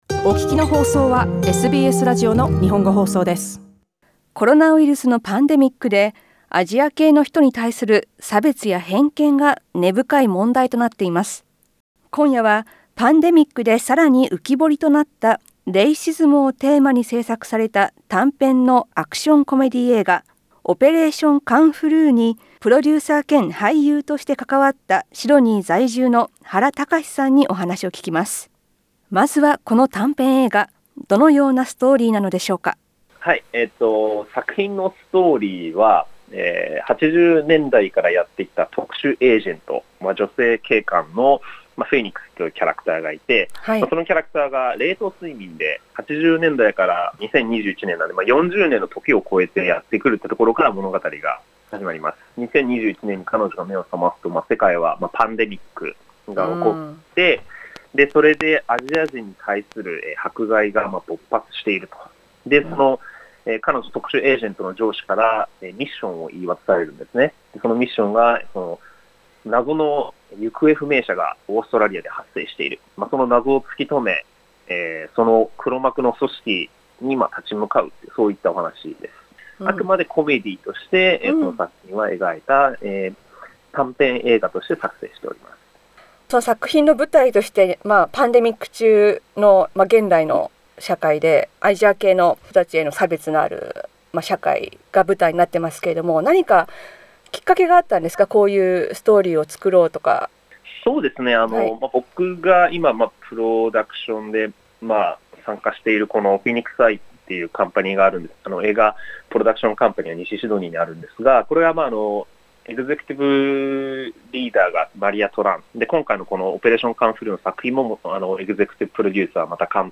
インタビューでは、短編映画を制作することになった経緯や、映画の内容、撮影の様子や役作りなどについて聞きました。